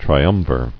[tri·um·vir]